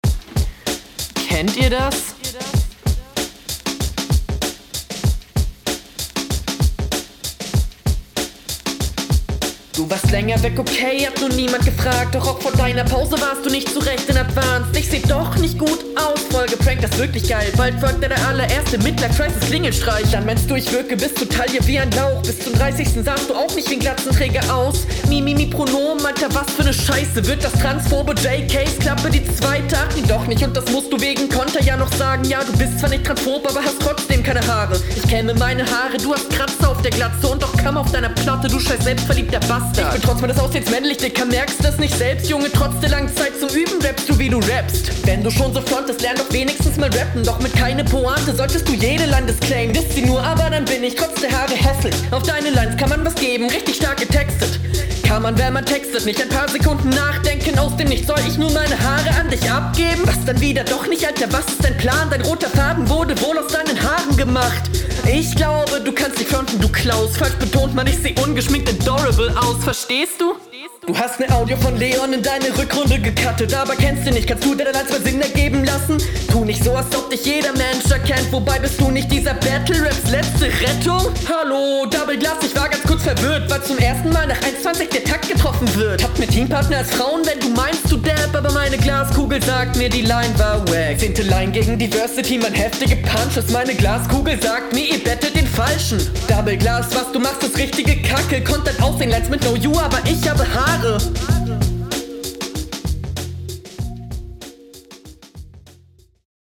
Beatintro komisch aber was solls. Finde der rappt gut auf den Beat.